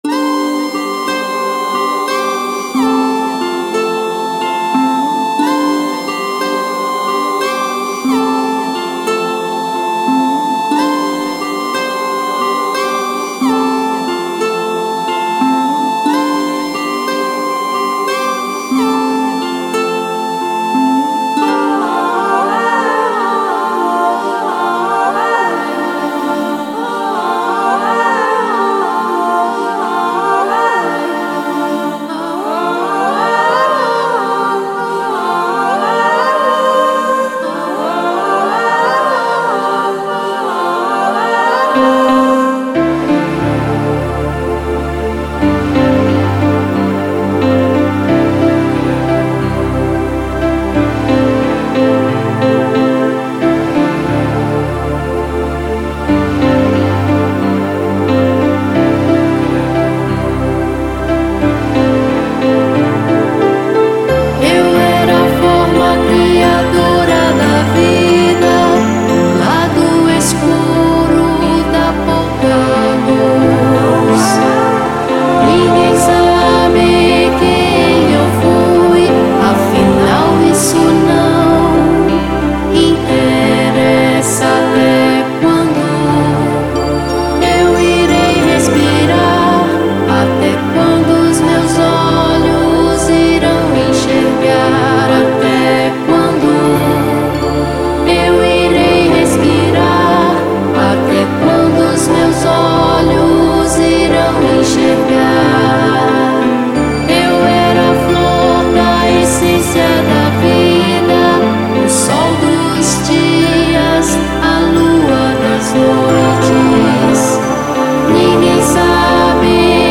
EstiloNew Age